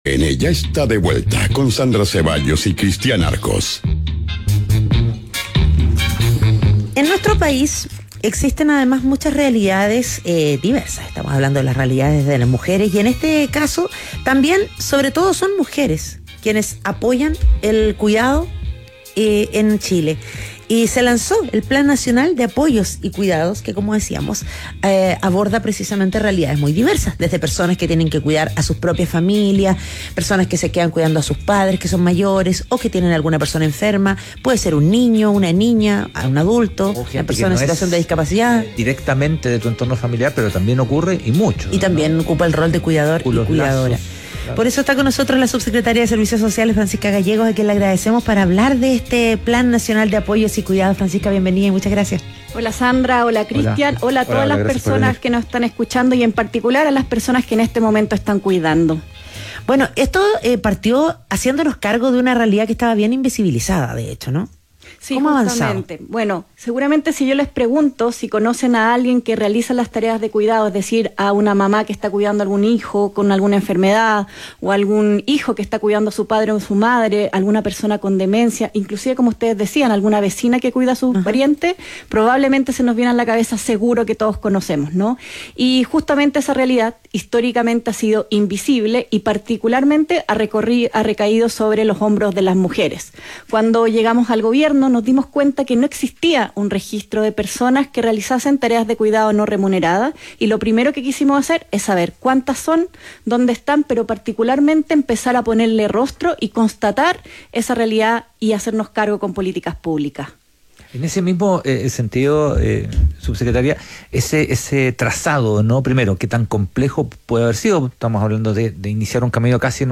La subsecretaria de Servicios Sociales, Francisca Gallegos, explicó en Ciudadano ADN los principales ejes de la iniciativa, que busca aliviar la carga de quienes dedican su vida al cuidado de otros.